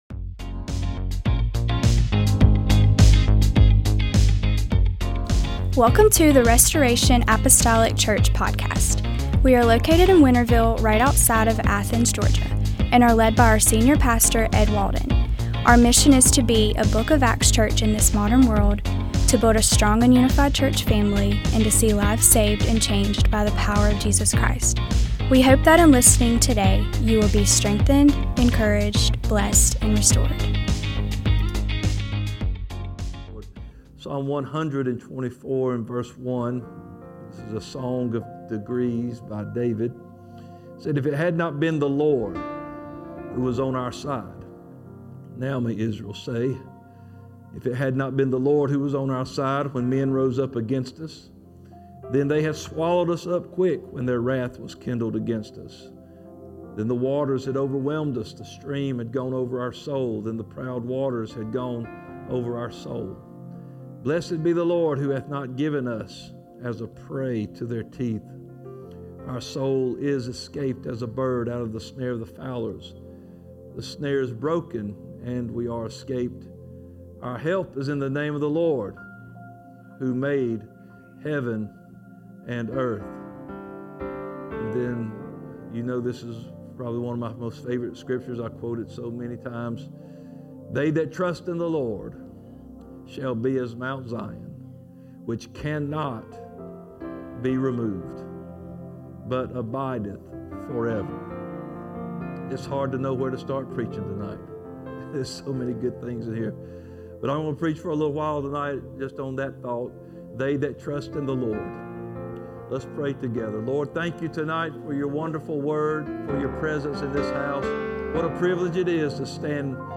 MDWK Bible Study